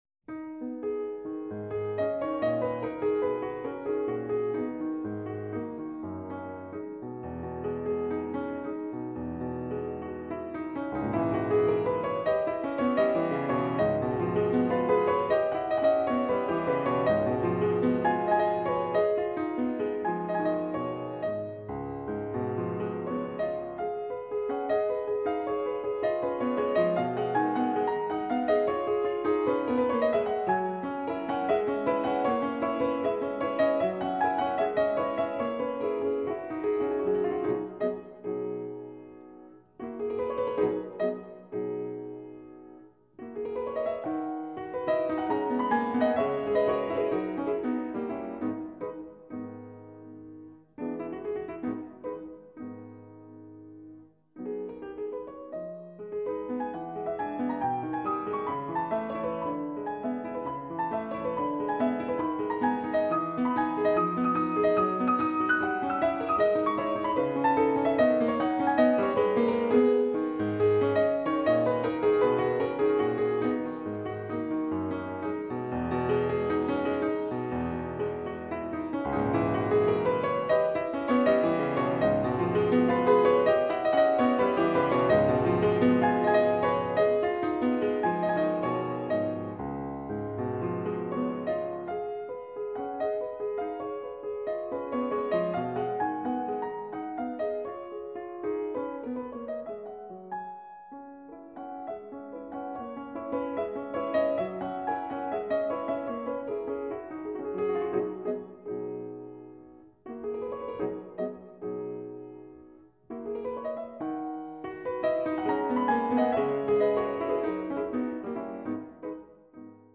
Kūriniai fortepijonui / Piano Works
fortepijonas / piano